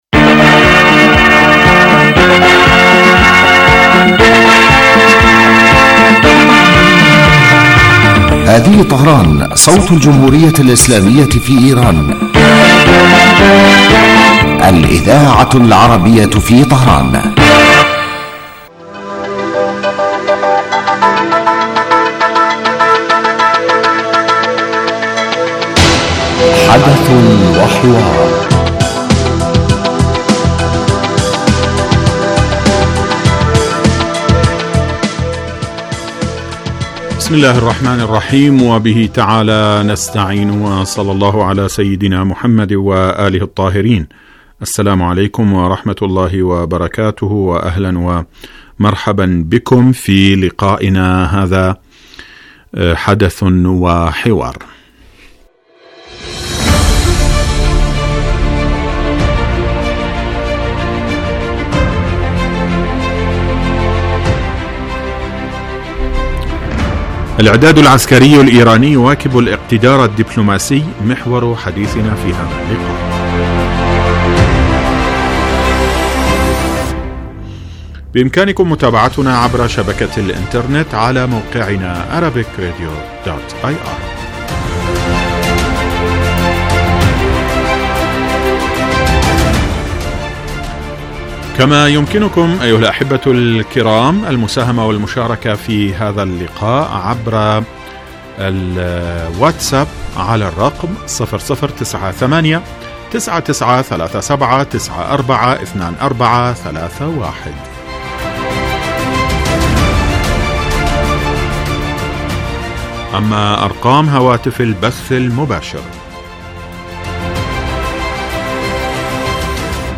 يبدأ البرنامج بمقدمة يتناول فيها المقدم الموضوع ثم يطرحه للنقاش من خلال تساؤلات يوجهها للخبير السياسي الضيف في الاستوديو . ثم يتم تلقي مداخلات من المستمعين هاتفيا حول الرؤى التي يطرحها ضيف الاستوديو وخبير آخر يتم استقباله عبر الهاتف ويتناول الموضوع بصورة تحليلية.